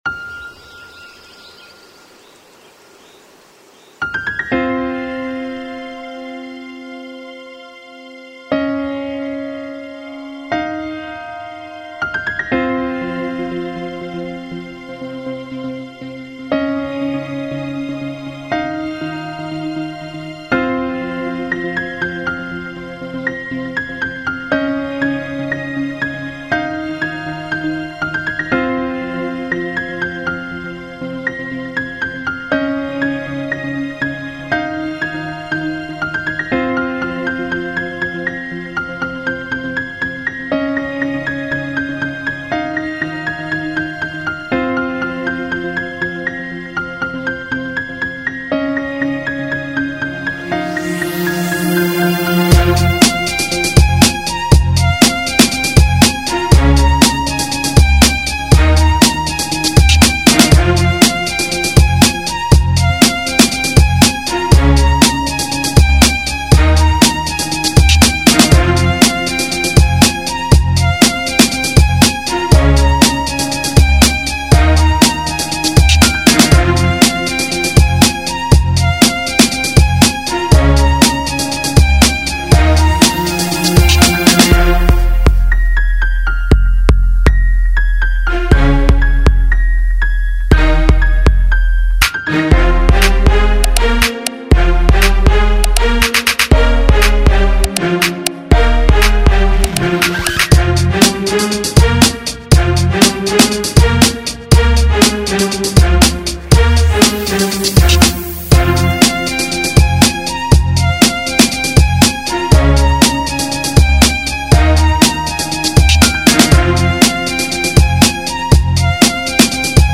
Instrumental_-_violin_piano_and_bites.mp3